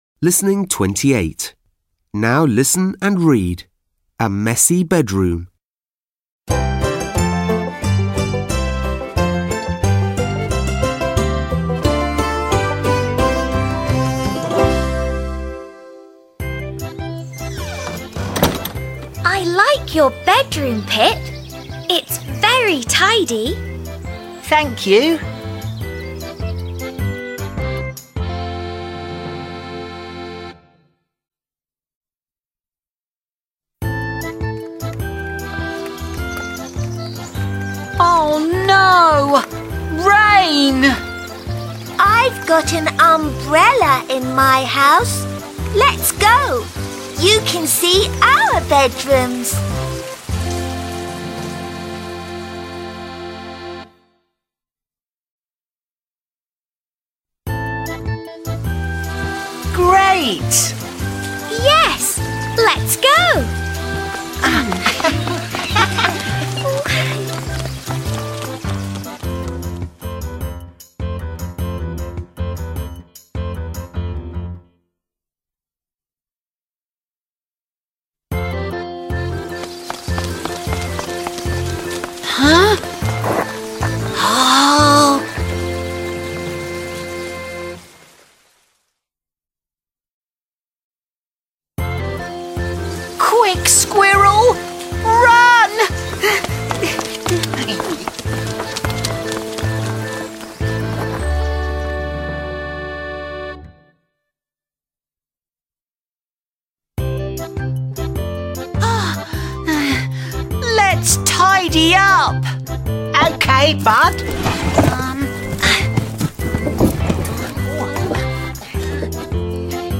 Słychać szelest liści (the leaves are rustling), śpiew ptaków (the birds are chirping), a wiatr szumi w koronach drzew (and the wind is blowing in treetops).